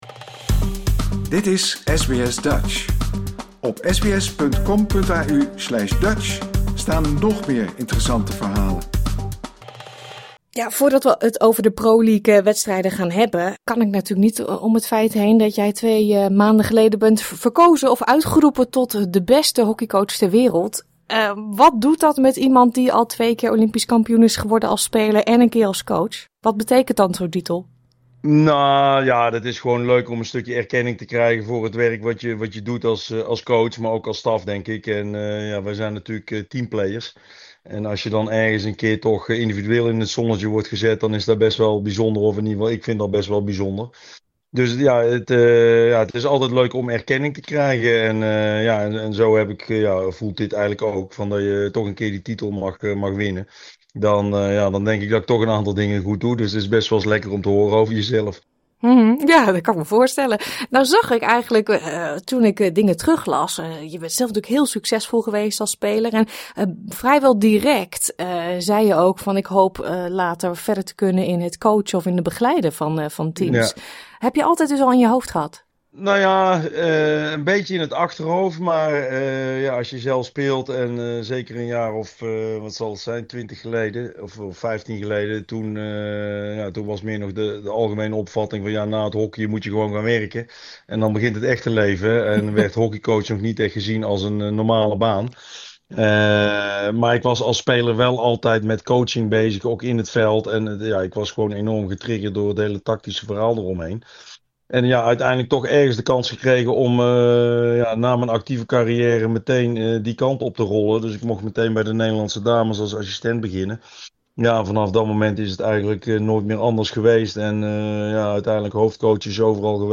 Op dit moment is hij met Oranje in Sydney voor de FIH Pro League wedstrijden tegen Spanje en Australië. Wij spraken met de door de Internationale Hockey Federatie uitgeroepen beste herencoach ter wereld. Hoe is het om terug te zijn in Sydney en hoe staat zijn elftal ervoor?